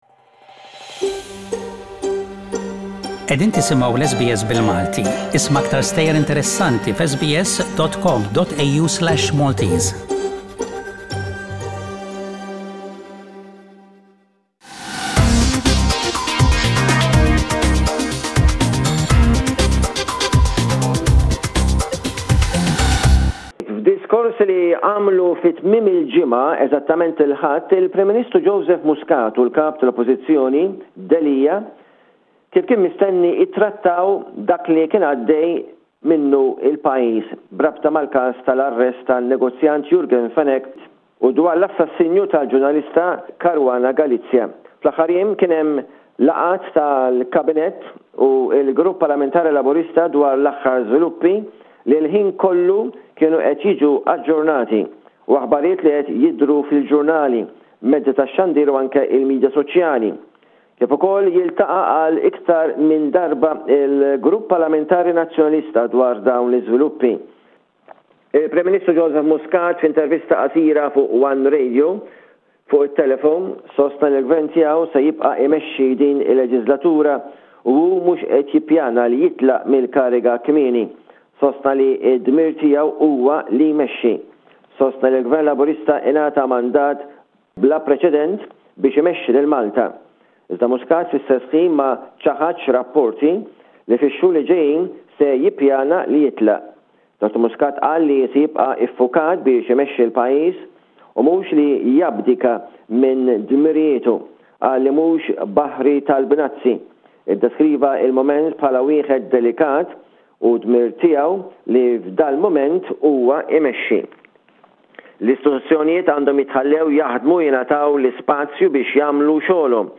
SBS radio correspondent